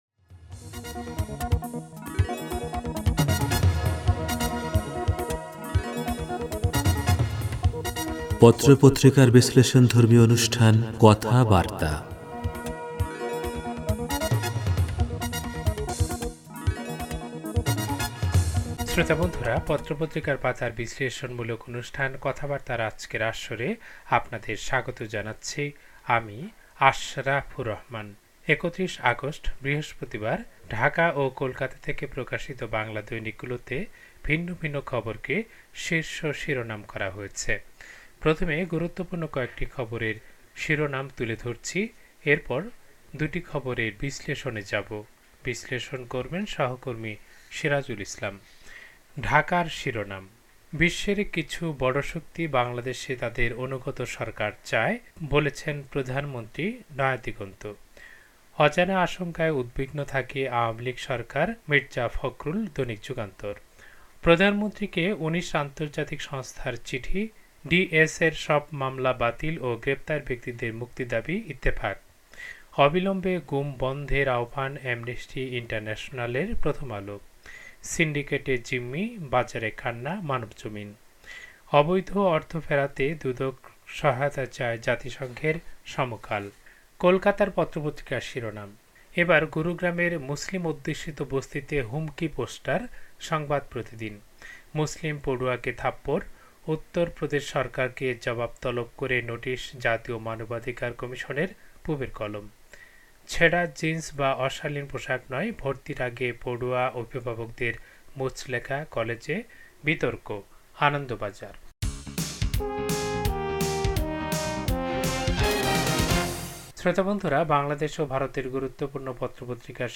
পত্রপত্রিকার পাতার অনুষ্ঠান কথাবার্তা